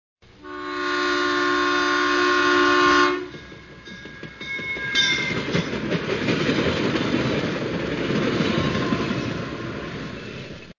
2 – نغمة صوت القطار